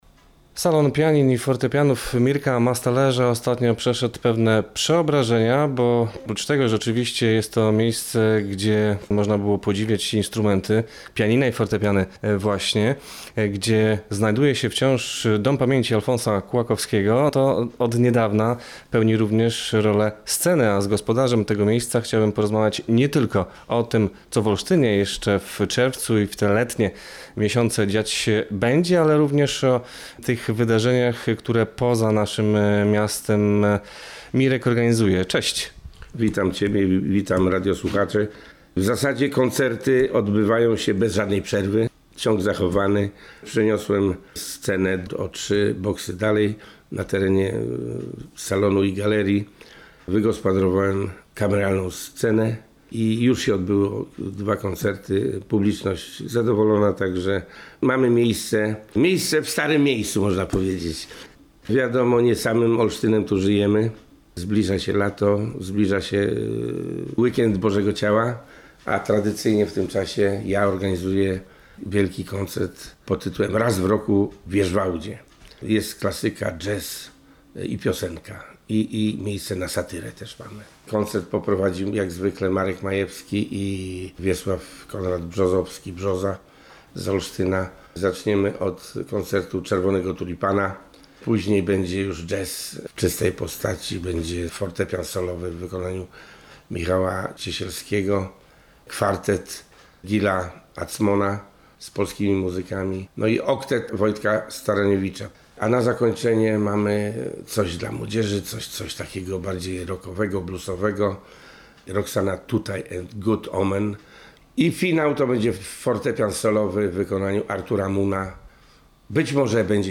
To nie wszystko, bo na jego olsztyńskiej scenie, dzieje się nie tylko ze względu na zmianę lokalizacji. Kto wystąpi w Stajniach Dragonów latem i wczesną jesienią? – posłuchajcie rozmowy.